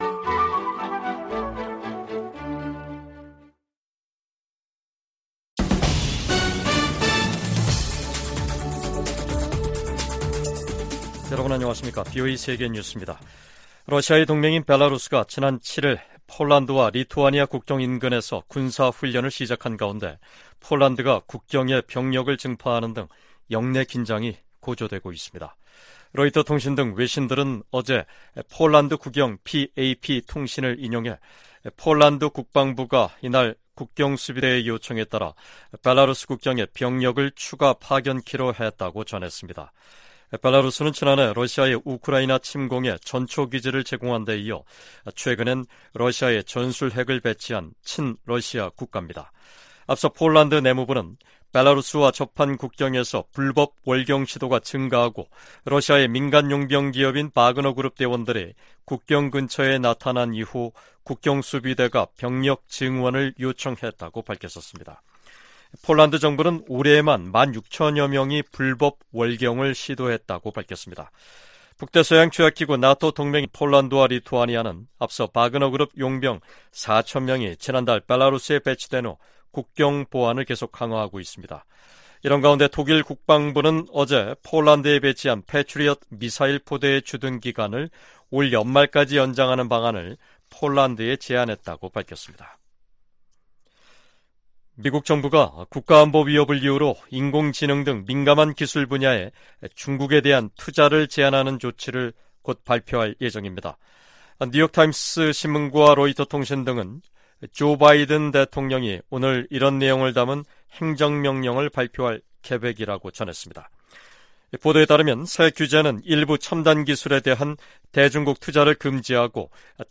VOA 한국어 간판 뉴스 프로그램 '뉴스 투데이', 2023년 8월 9일 2부 방송입니다. 미 국방부는 북한과의 무기 거래는 불법이라며 대가가 따를 것이라고 경고했습니다. 중국 중고 선박이 북한으로 판매되는 사례가 잇따르고 있는 가운데 국무부는 기존 대북제재를 계속 이행할 것이라는 입장을 밝혔습니다. 다음 주 미국에서 열리는 미한일 정상회의를 계기로 3국 정상회의가 정례화될 가능성이 높다고 미국 전문가들이 전망했습니다.